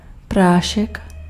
Ääntäminen
Ääntäminen France: IPA: [pudʁ] Haettu sana löytyi näillä lähdekielillä: ranska Käännös Ääninäyte Substantiivit 1. prach {m} 2. prášek Suku: f .